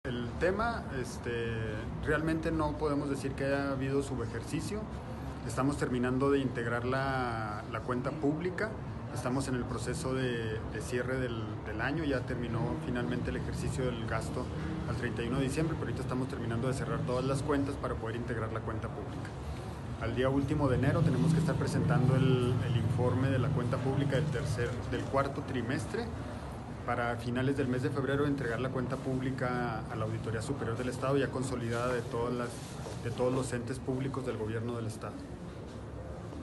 AUDIO: JOSÉ DE JESÚS GRANILLO, TITULAR DE LA SECRETARÍA DE HACIENDA ESTATAL
JOSE-DE-JESUS-GRANILLOSUBEJERCISIOS.mp3